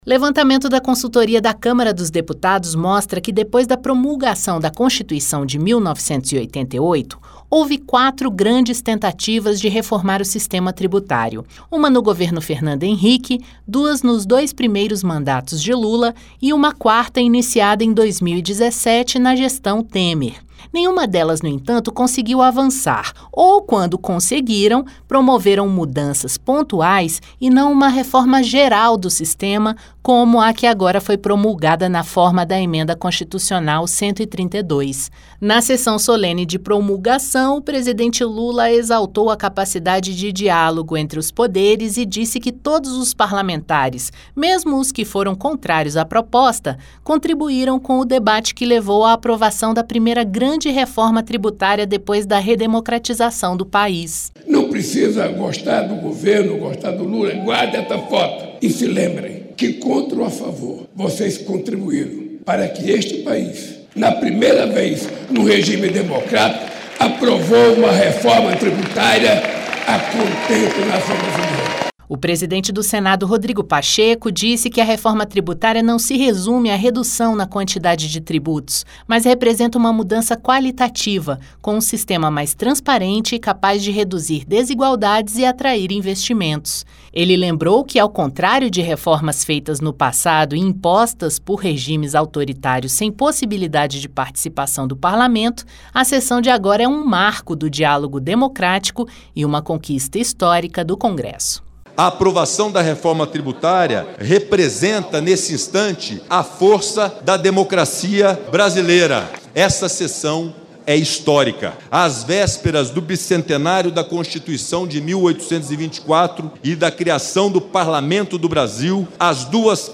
Autoridades presentes na sessão de promulgação da Emenda Constitucional 132, da reforma tributária, na quarta-feira (20), destacaram o caráter democrático do debate que levou à aprovação da matéria. O presidente Lula lembrou que esta é a primeira grande reforma de impostos depois da redemocratização do país. O presidente do Congresso e do Senado, Rodrigo Pacheco, classificou o momento como "histórico" e uma conquista do Parlamento às vésperas de seu bicentenário.